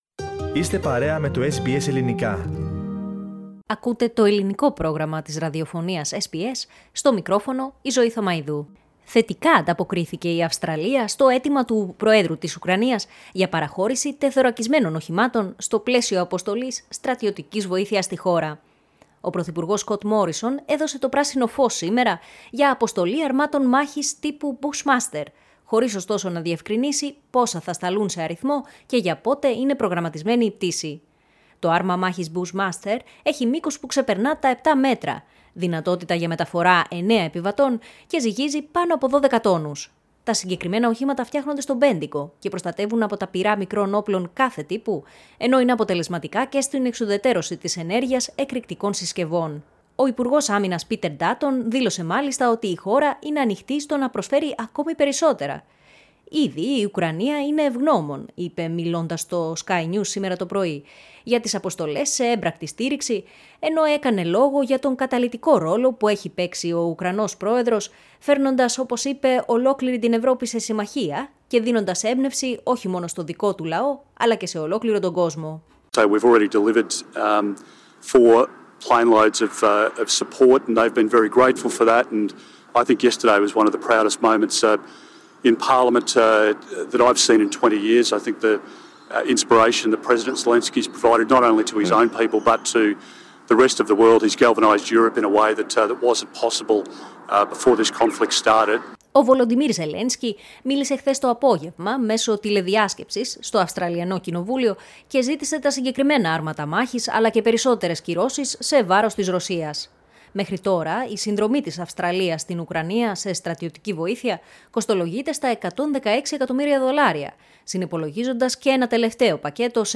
Ακούστε στο podcast ολόκληρο το ρεπορτάζ με τις δηλώσεις του υπουργού, το χειρότερο σενάριο σύμφωνα με τον Ουκρανό πρόεδρο και τις τελευταίες εκτιμήσεις Δυτικών ηγετών.